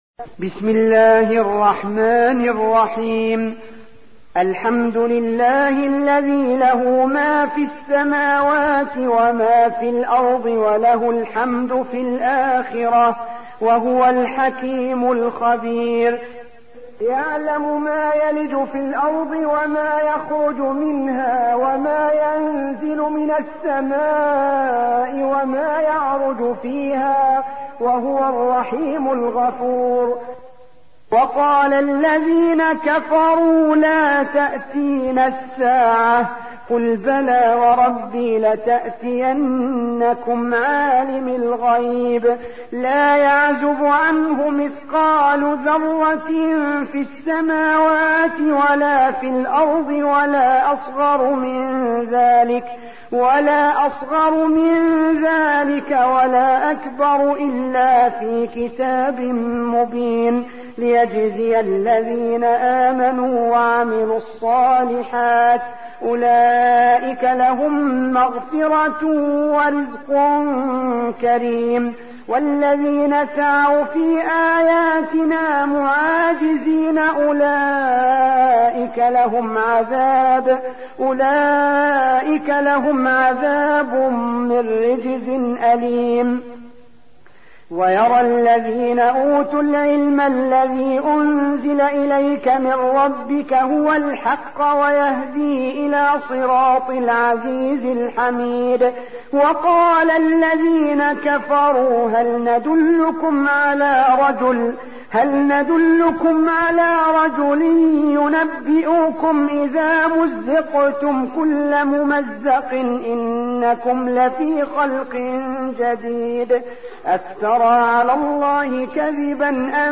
34. سورة سبأ / القارئ